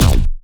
stomp_01.wav